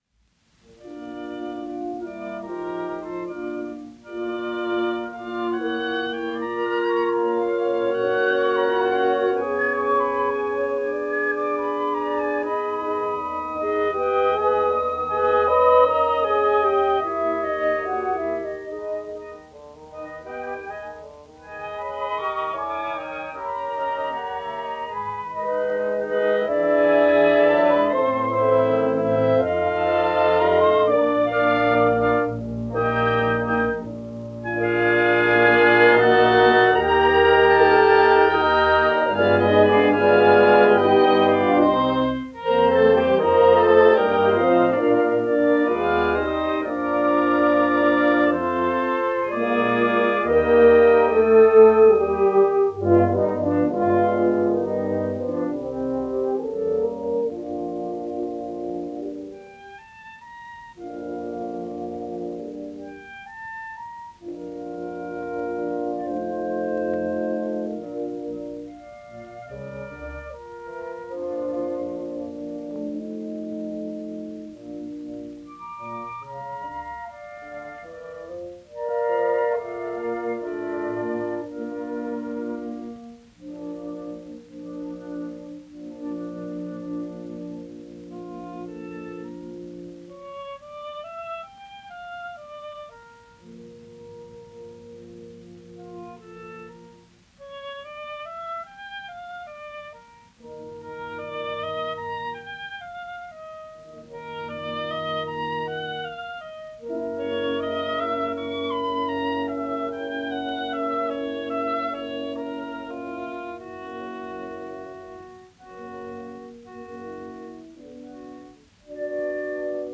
Format: 78 RPM set
Date Recorded: 21 May 1936, in New York